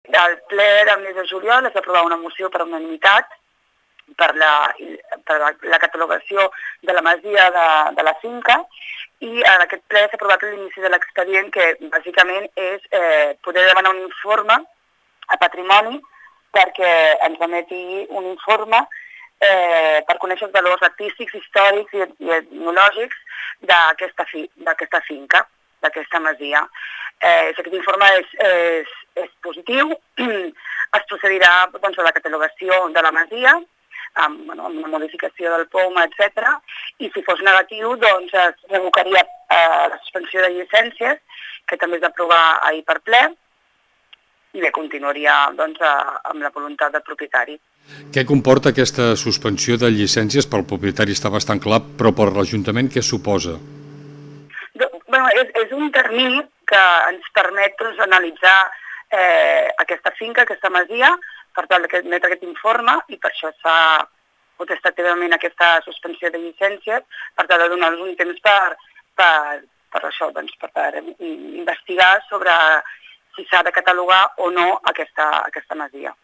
Dels detalls de tot plegat n’hem parlat amb Mireia Castellà, regidora d’Urbanisme a Malgrat de Mar.